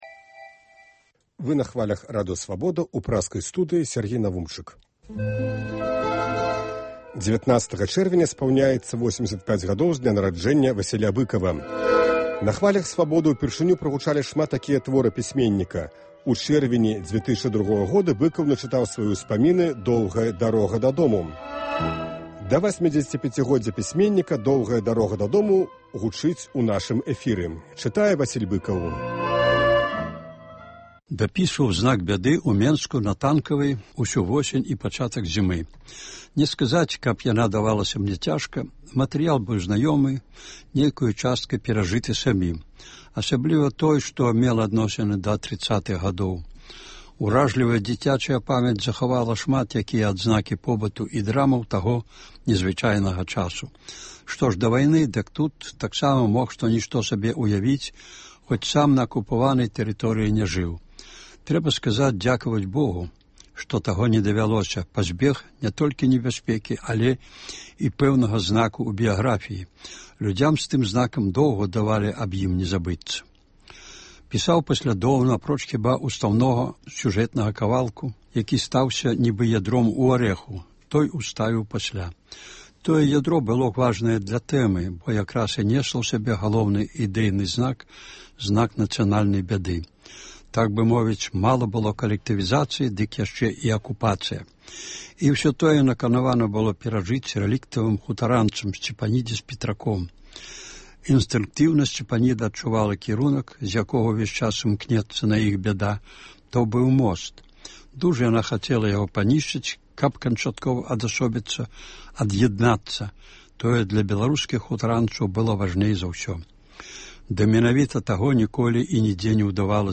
19 чэрвеня спаўняецца 85 гадоў з дня нараджэньня Васіля Быкава. Сёлета ў чэрвені штодня ў нашым эфіры гучыць “Доўгая дарога дадому” ў аўтарскім чытаньні.